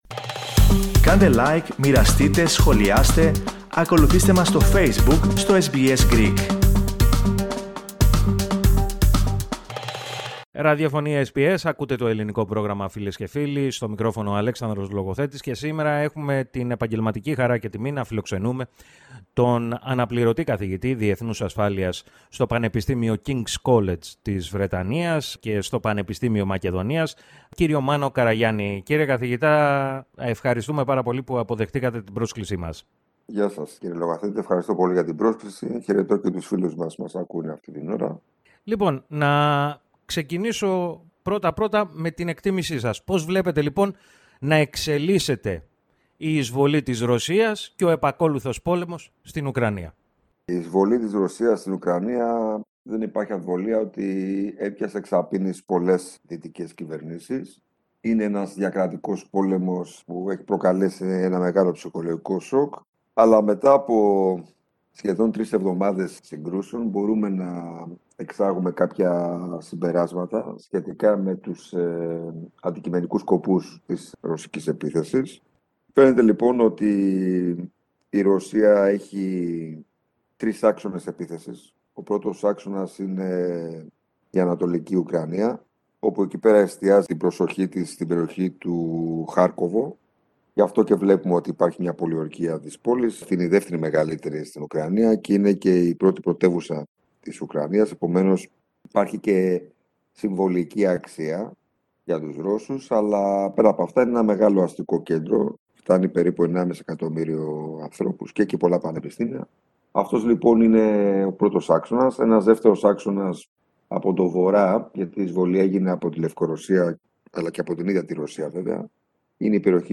Συνέντευξη εφ’ όλης της ύλης